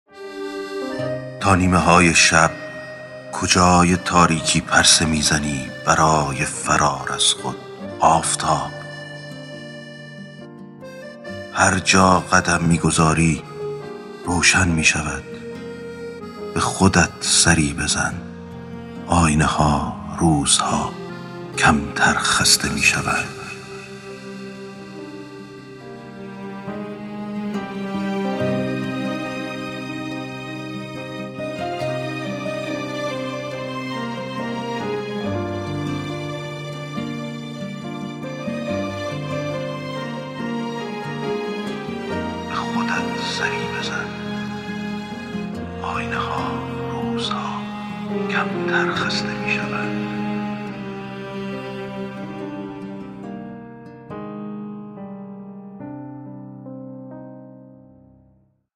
دانلود دکلمه به خودت سری بزن افشین یداللهی
گوینده :   [افشین یداللهی]